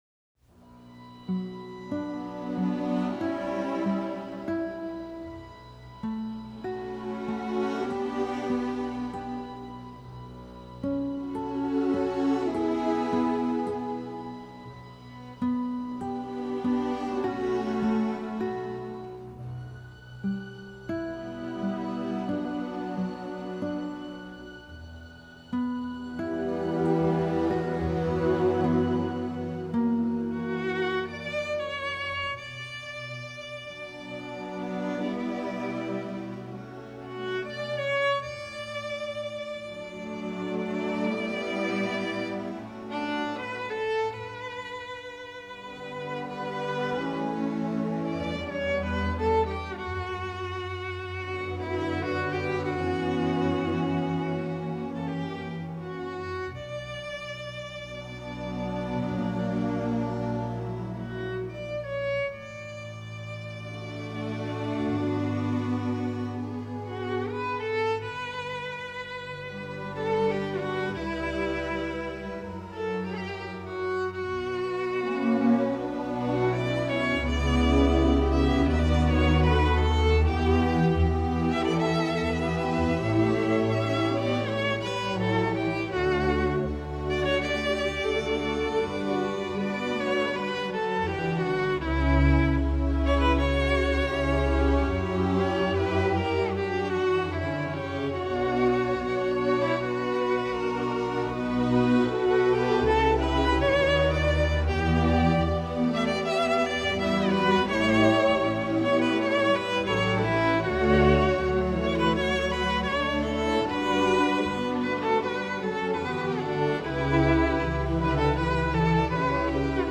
Voicing: Guitar and String Orchestra